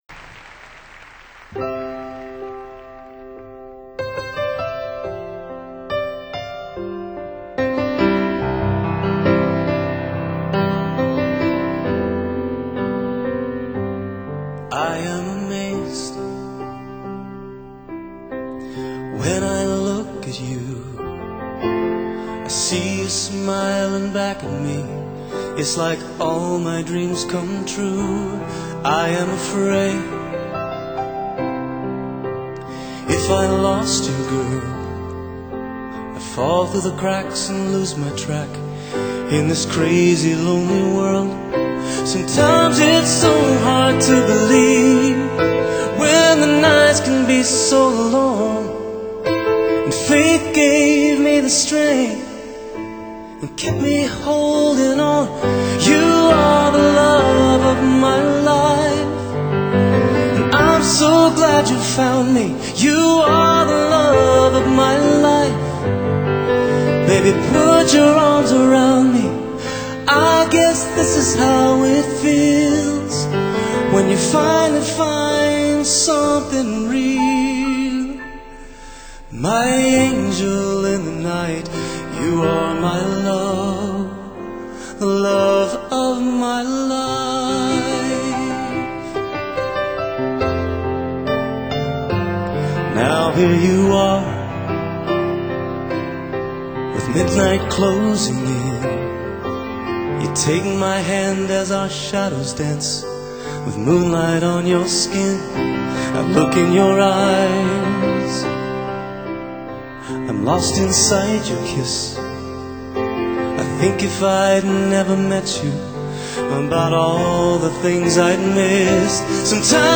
recorded live in Salt Lake City in March, 2000.